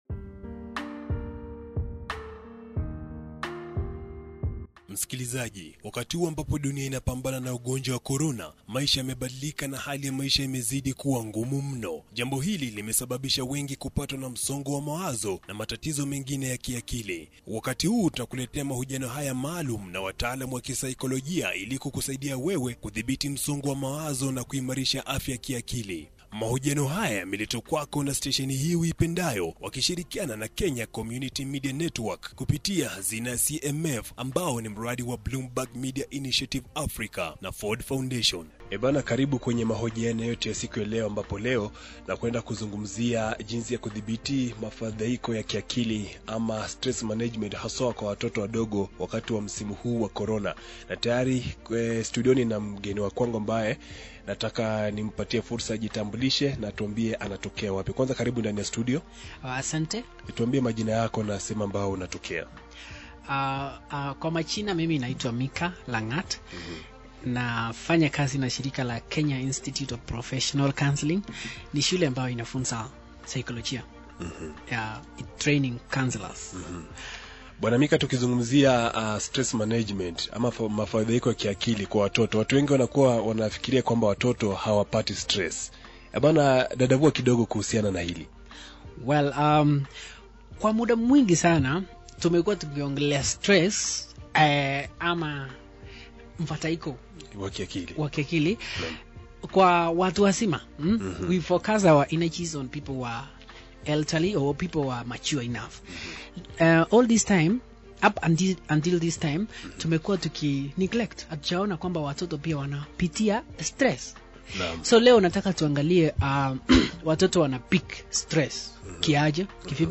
Listen to this Swahili interview done by Koch FM